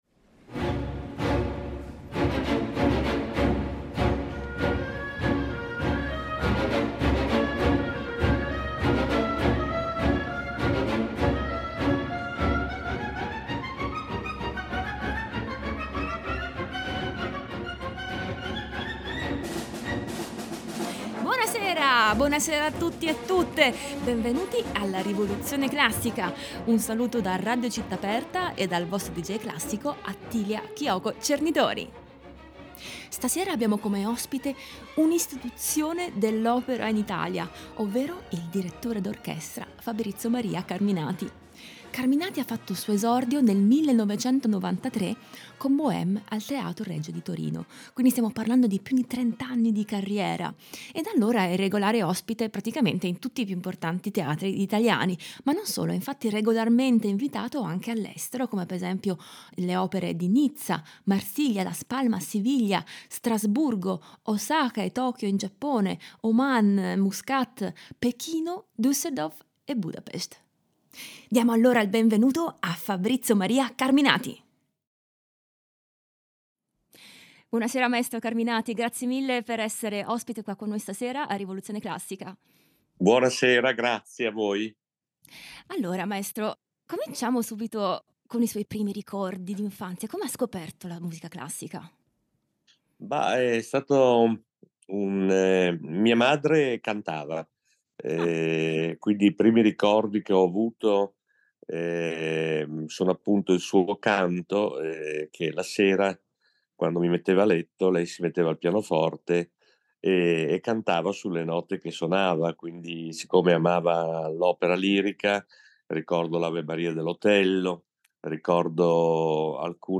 Ospite di questa puntata il direttore d'orchestra